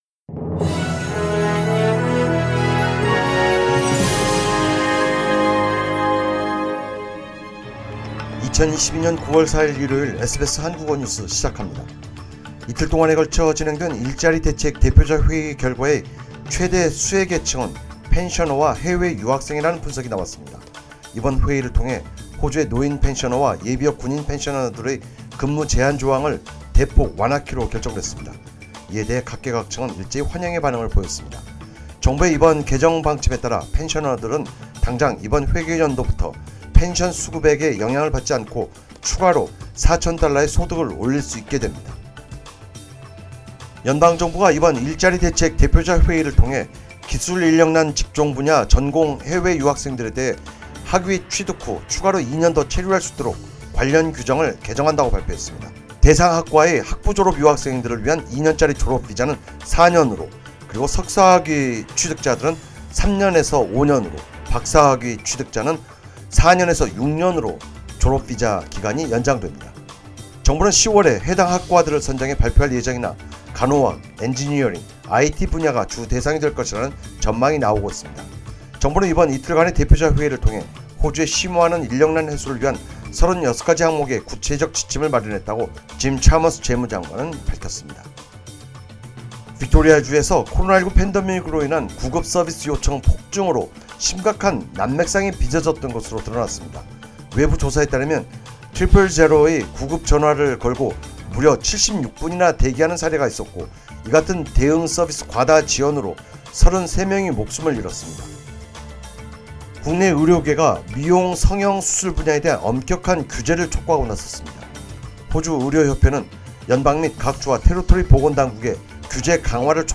SBS Korean News...4 September 2022